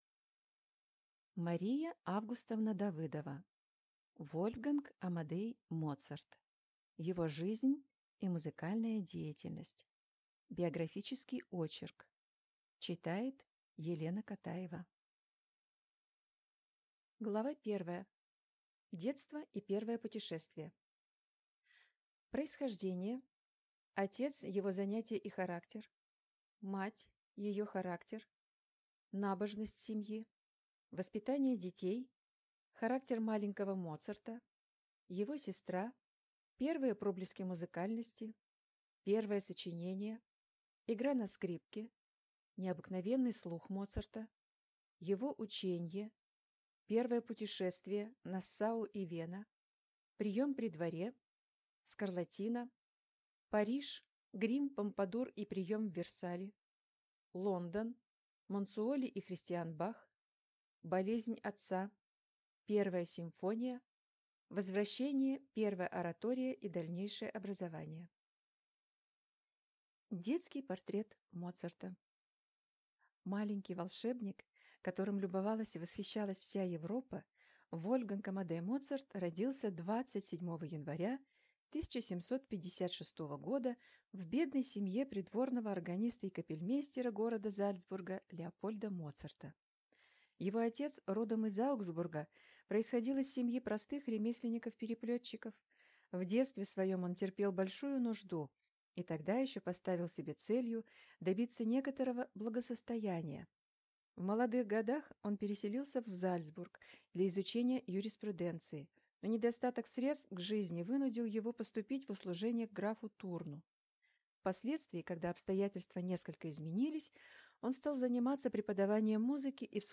Аудиокнига Вольфганг Амадей Моцарт. Его жизнь и музыкальная деятельность | Библиотека аудиокниг